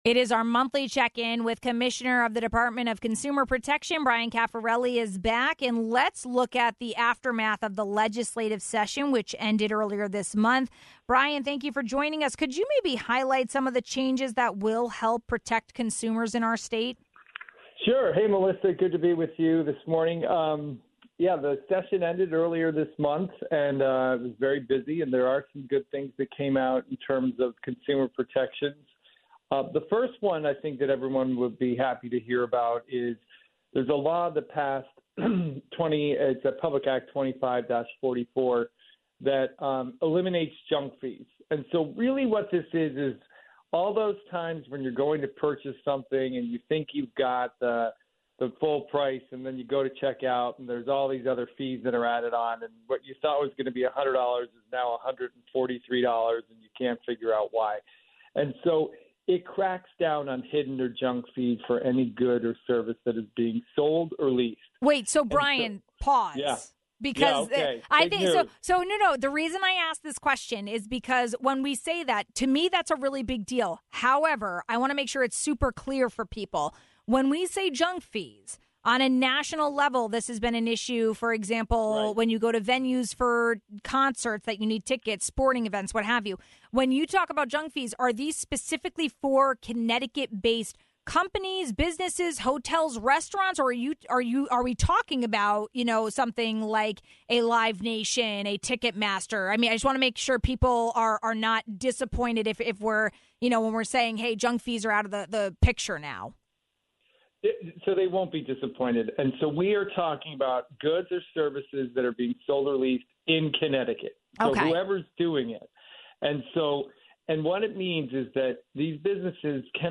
We had our monthly check-in with Dept. of Consumer Protection Commissioner Bryan Cafferelli. We talked about new protections laws that passed during the legislative session as well as summer scams to look out for.